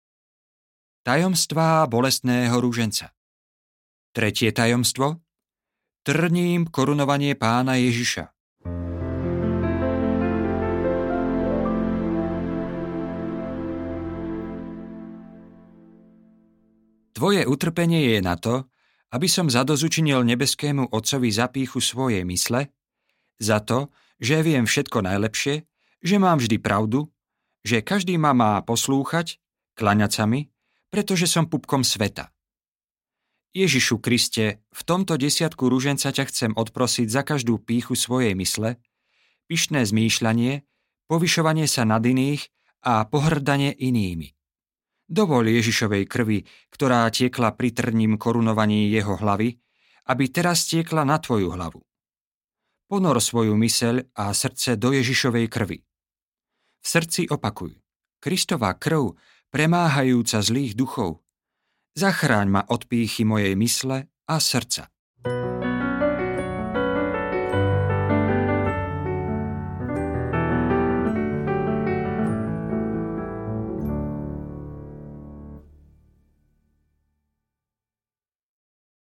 Ruženec - záchrana pre svet audiokniha
Ukázka z knihy